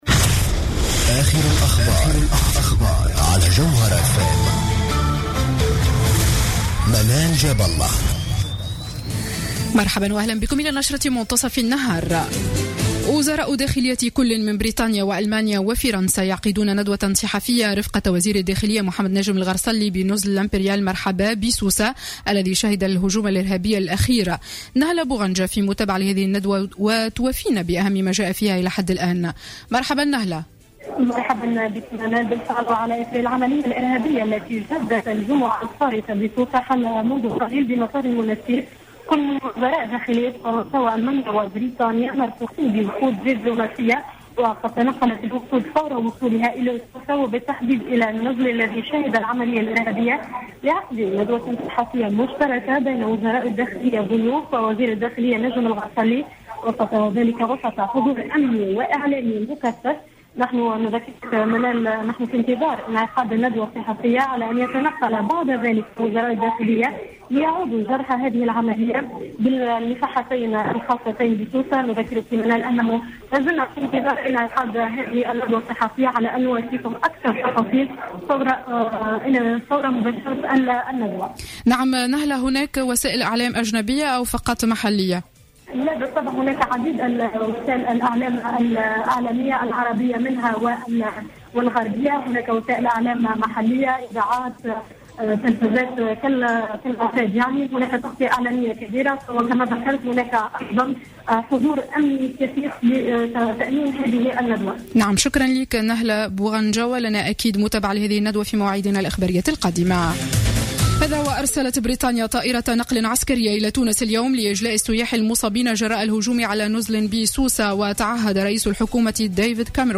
نشرة أخبار منتصف النهار ليوم الاثنين 29 جوان 2015